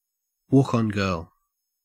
Ääntäminen
Ääntäminen AU RP : IPA : /ˌwɔːk ɒn ˈɡɜːl/ GA : IPA : /ˌwɔk ɑn ˈɡɝl/ Canada: IPA : /ˌwɑk ɑn ˈɡɝl/ Haettu sana löytyi näillä lähdekielillä: englanti Käännöksiä ei löytynyt valitulle kohdekielelle. Määritelmät Substantiivi ( darts ) A woman who escorts a player to the stage at a darts event .